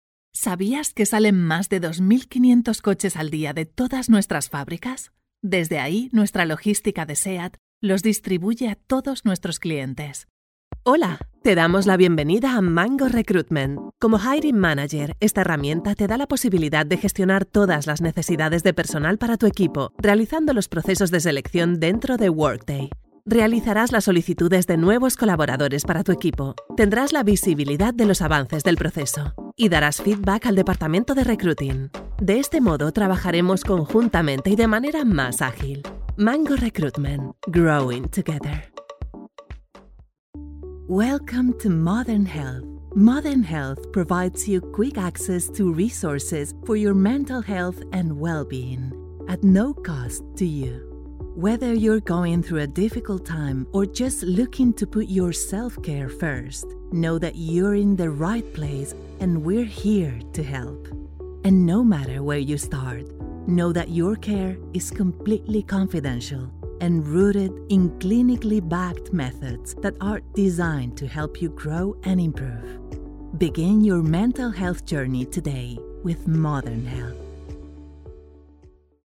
CORPORATE/EXPLAINER
Professional Home studio with specific Voiceover equipment.
– Isolated Sound booth Vicoustic
– Sennheiser MK4 microphone
CORPORATE-EXPLAINER.mp3